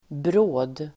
Ladda ner uttalet
bråd adjektiv, busy , hasty , sudden Uttal: [brå:d]